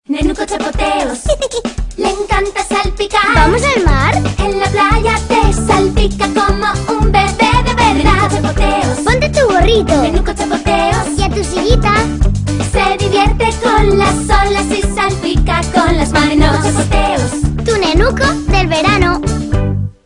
Siempre los últimos TONO DE ANUNCIOS